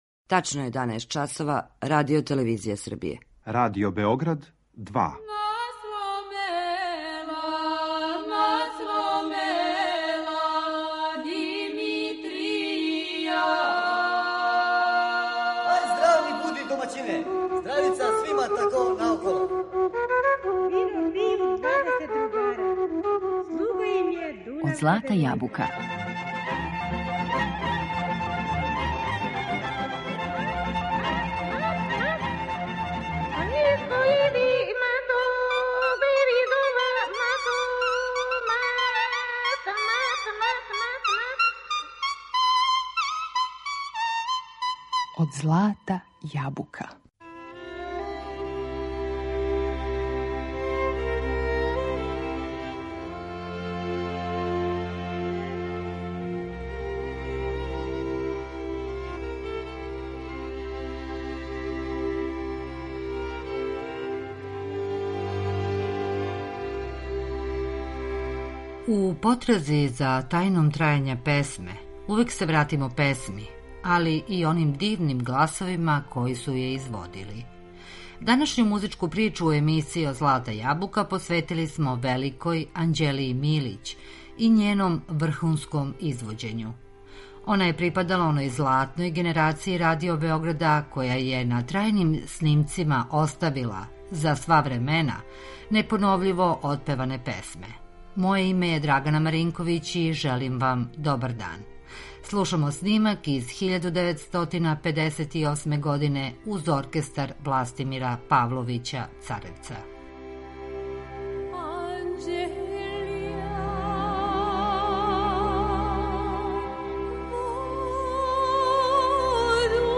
У потрази за тајном трајања песме, увек се вратимо самој песми и оним дивним гласовима који су је изводили.